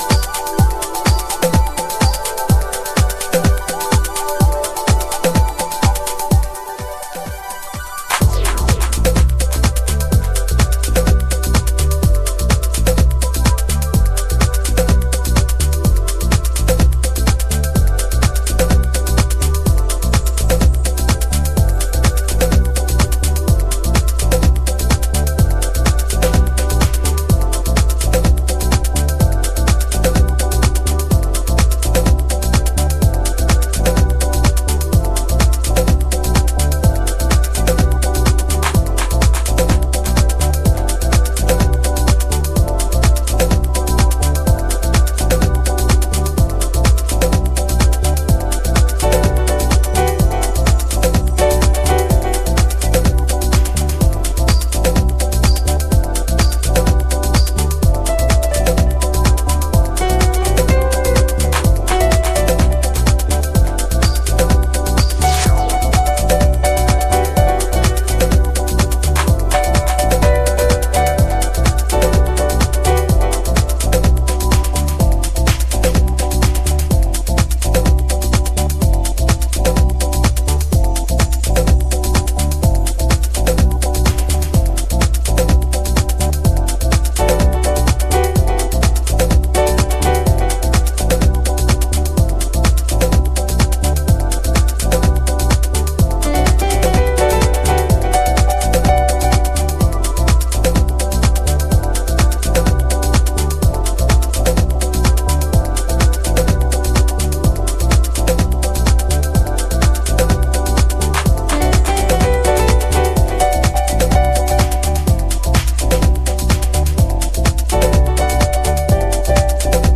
House / Techno
シンセでトロピカルを表現した陽性ハウス名作。